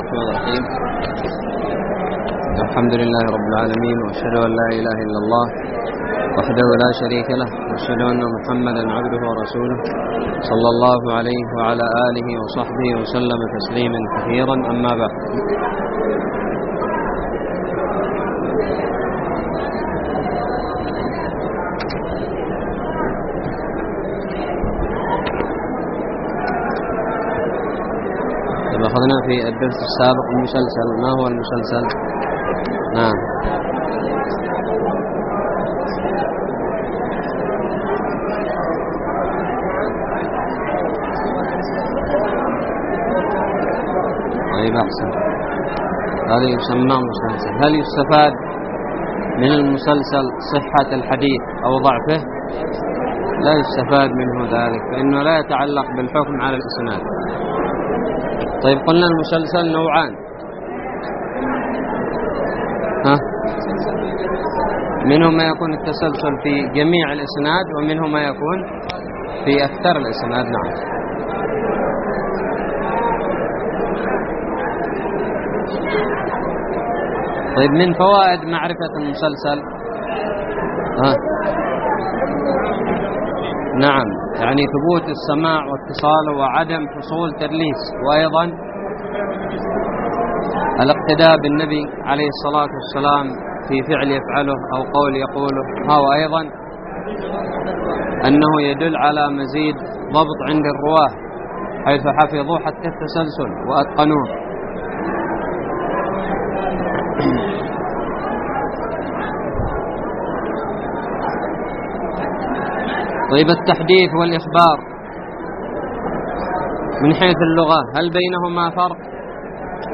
الدرس الرابع والأربعون من شرح كتاب نزهة النظر
ألقيت بدار الحديث السلفية للعلوم الشرعية بالضالع